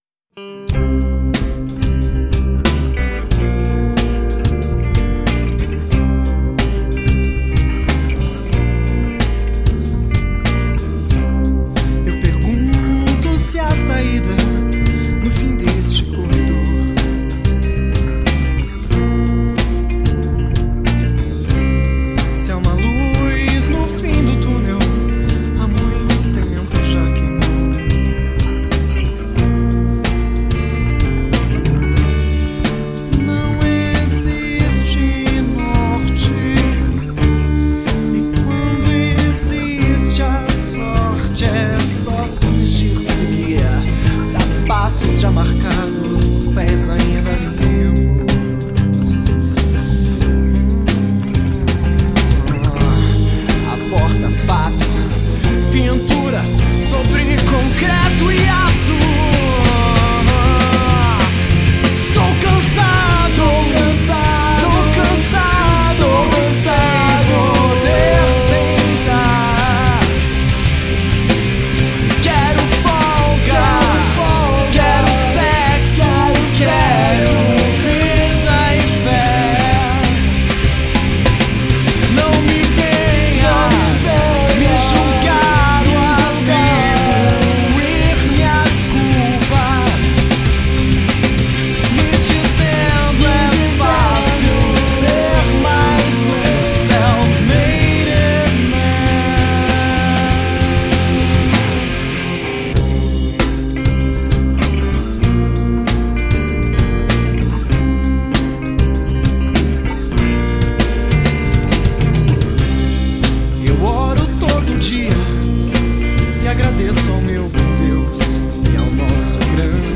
EstiloHard Rock
Cidade/EstadoSão Paulo / SP